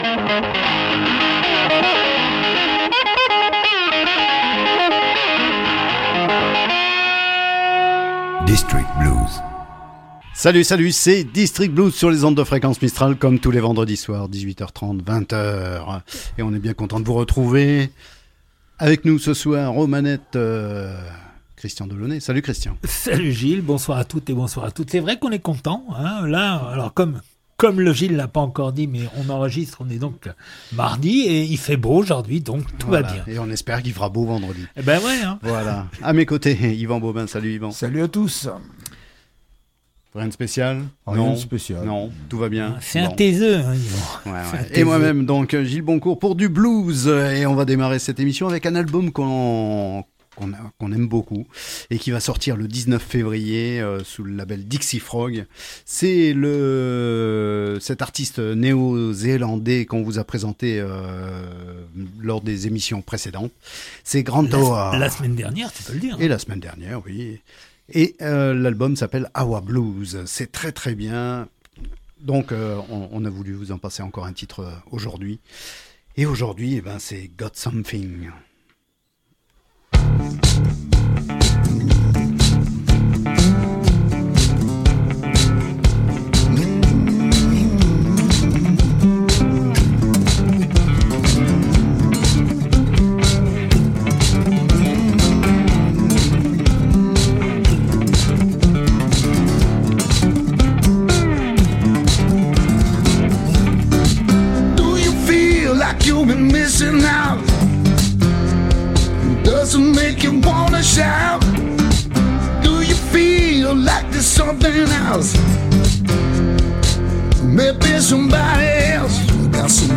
district blues du 2021-02-10.mp3 (60.73 Mo) Le blues sous toutes ses formes, le blues sous toutes ses faces, voilà le credo d’Eden District Blues, qu’il vienne de Chicago, de Milan, du Texas ou de Toulouse, qu’il soit roots, swamp, rock ou du delta…
« DISTRICT BLUES », une émission hebdomadaire, tous les vendredis à 18h30 et rediffusée le mercredi à 23h00 .